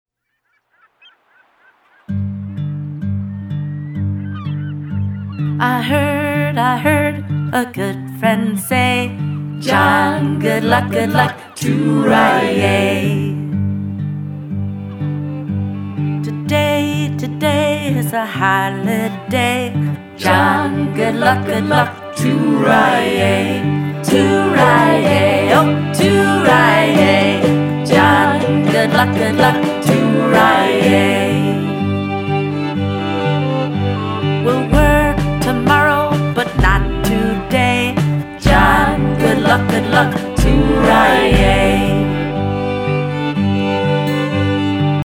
recorded in a small recording studio